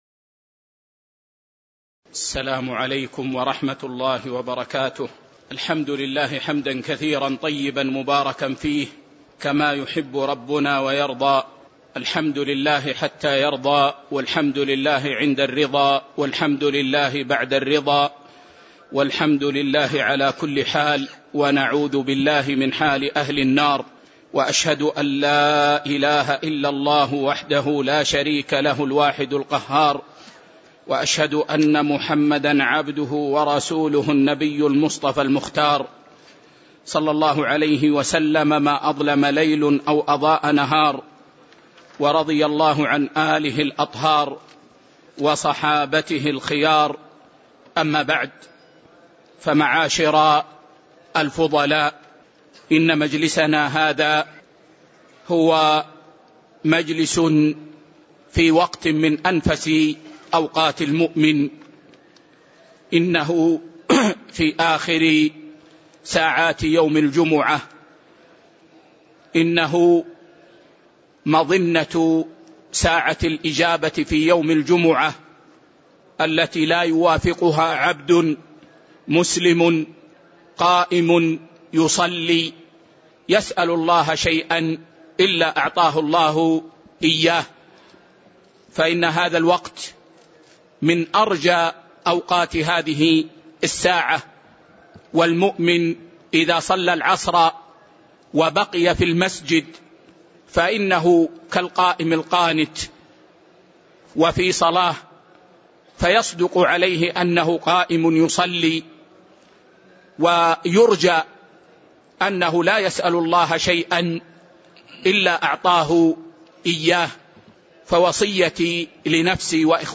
تاريخ النشر ٢٨ محرم ١٤٤١ هـ المكان: المسجد النبوي الشيخ